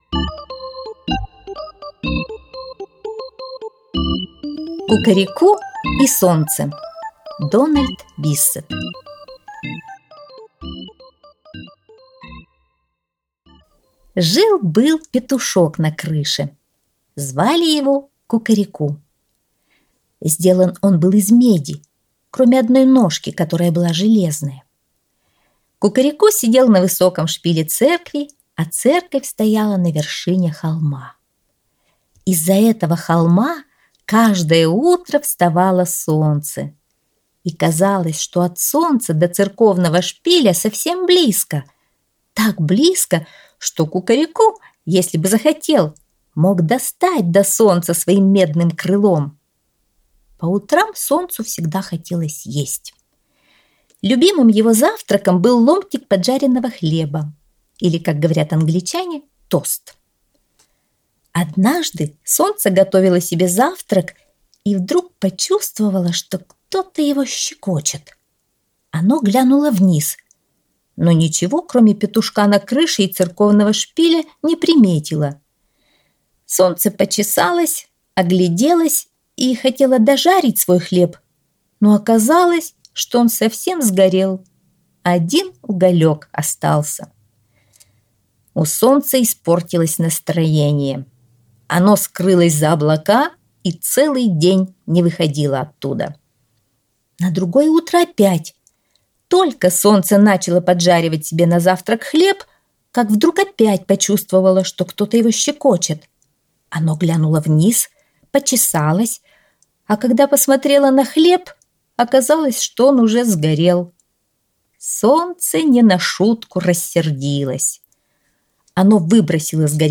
Аудиосказка «Кукареку и Солнце»